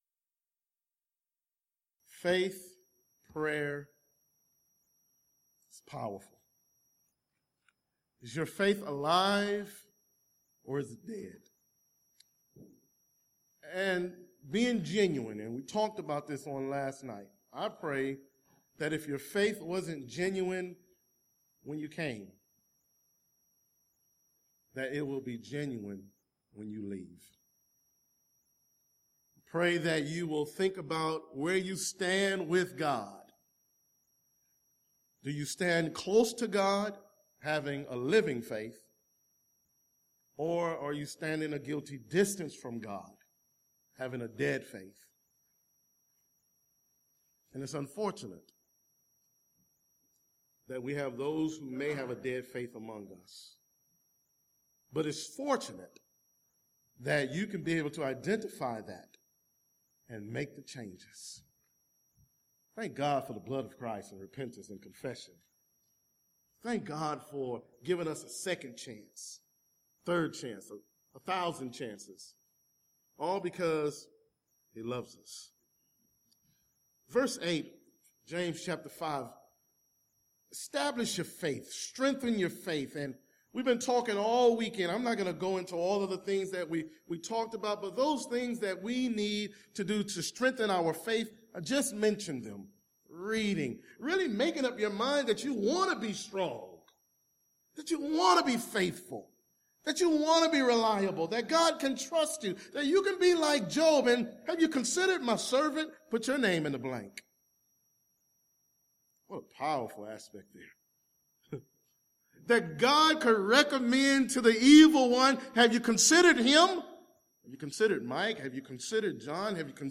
Alternate File Link File Details: Series: Discipleship University Event: Discipleship University 2013 Theme/Title: Dead or Alive: Lessons about faith from a man who served Jesus.
Youth Sessions